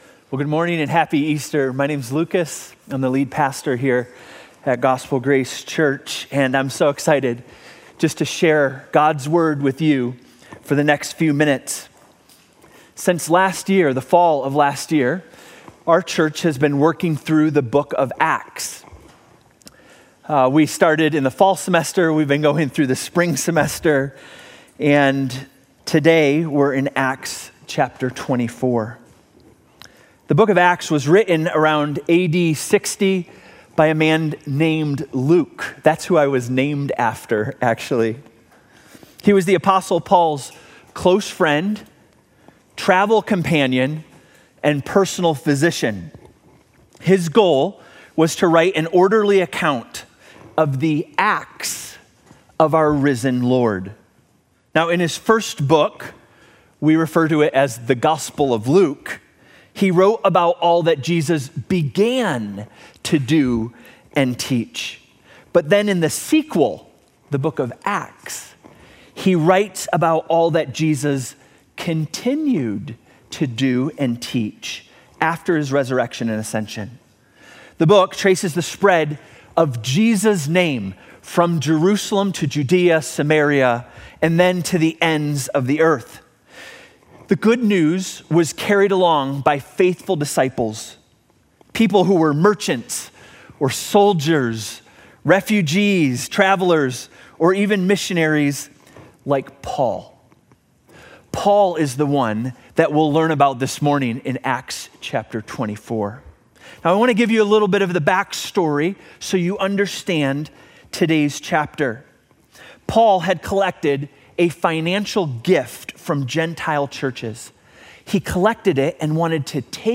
Gospel Grace Church Sermon Audio